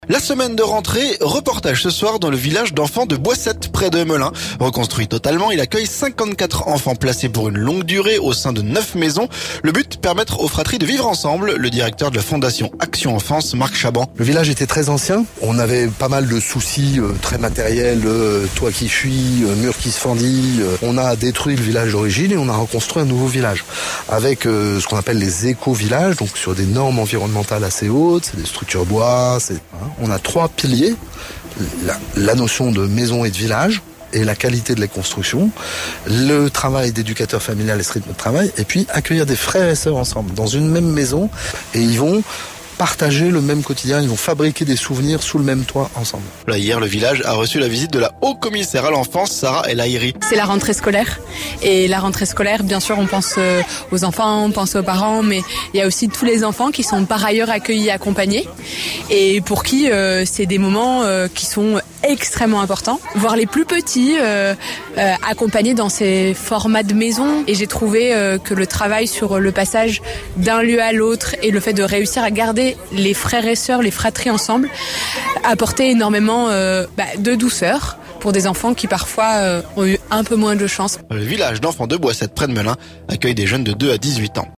La semaine de rentrée...Reportage dans le village d'enfants de Boissettes, près de Melun...